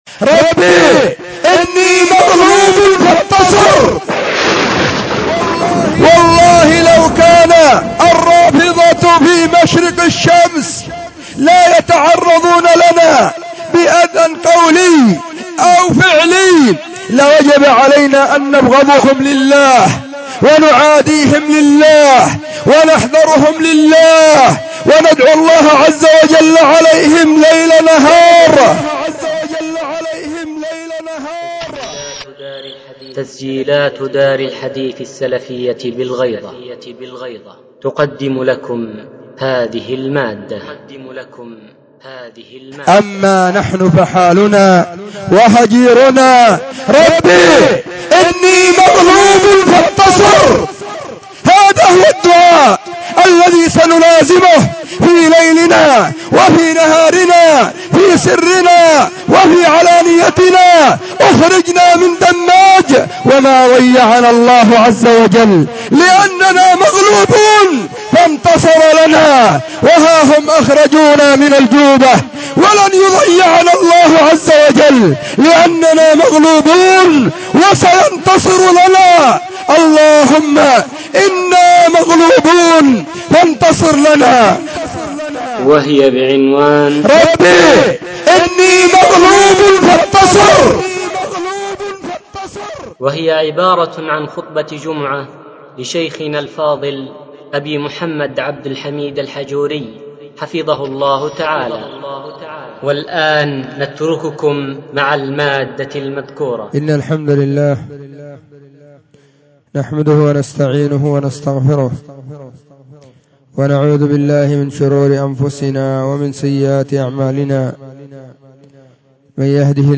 خطبة جمعة بعنوان : *🪴فدَعَا رَبَّهُ أَنِّي مَغْلُوبٌ فَانْتَصِرْ🪴*
📢 وكانت – في – مسجد – الصحابة – بالغيضة – محافظة – المهرة – اليمن.